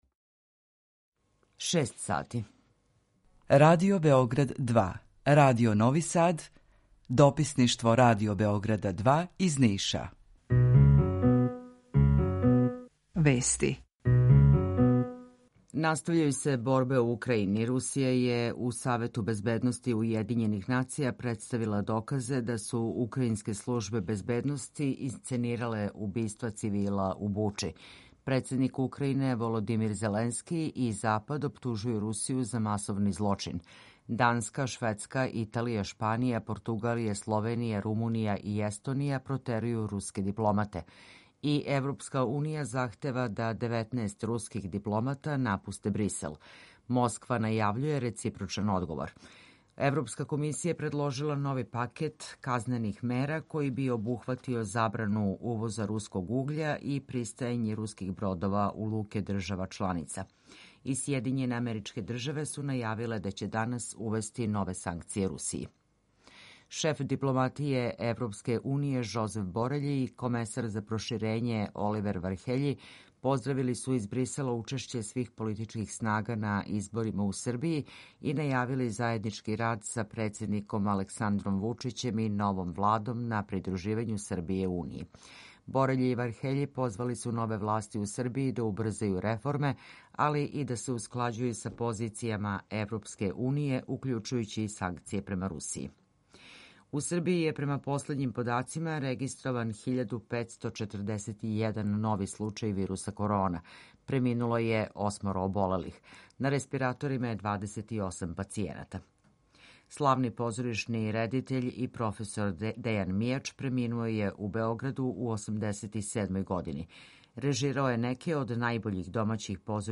Укључење из Грачанице
У два сата, ту је и добра музика, другачија у односу на остале радио-станице.